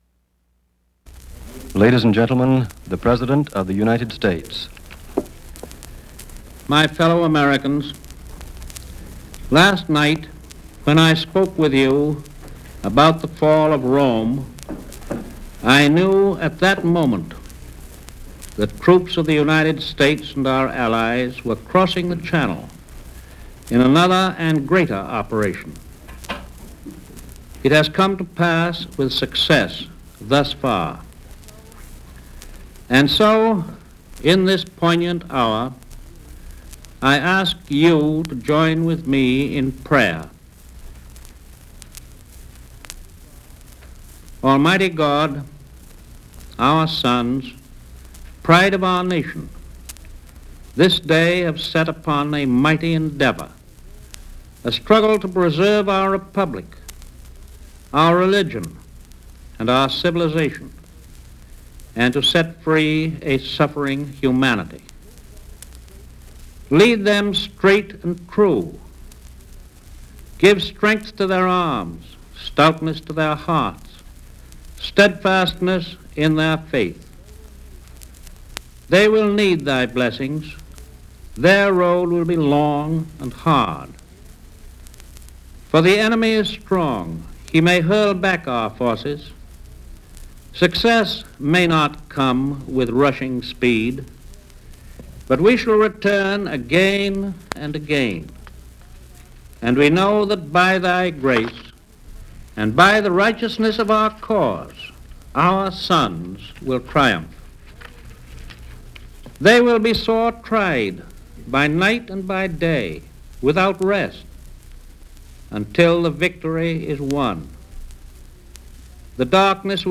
U.S. President Franklin D. Roosevelt delivers a D-Day speech and prayer
Franklin D. Roosevelt makes an announcement of troops crossing channel on D-Day and offers a prayer. Read In Collections G. Robert Vincent Voice Library Collection Copyright Status No Copyright Date Published 1944-06-06 Speakers Roosevelt, Franklin D. (Franklin Delano), 1882-1945 Broadcasters WJZ (Radio station : New York, N.Y.) Subjects World War (1939-1945) Military campaigns France--Normandy Material Type Sound recordings Language English Extent 00:06:23 Venue Note Broadcast 1944 June 6.